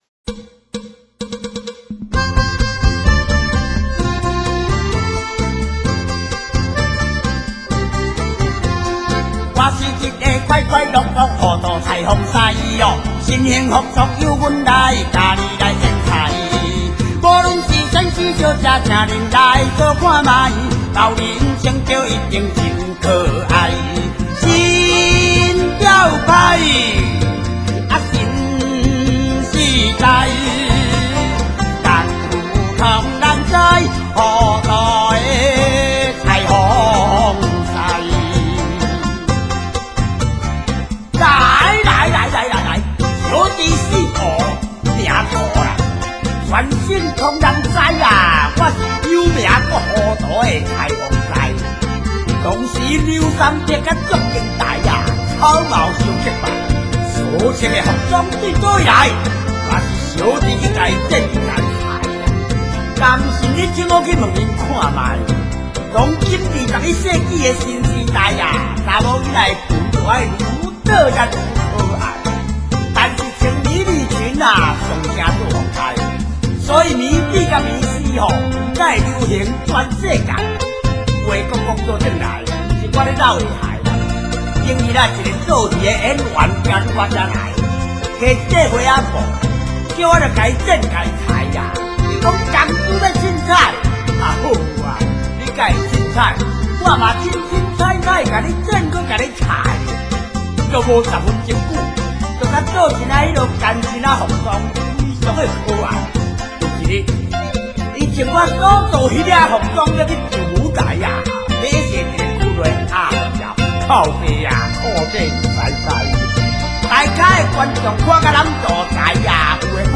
另附男声试听：